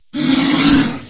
PixelPerfectionCE/assets/minecraft/sounds/mob/polarbear/death1.ogg at mc116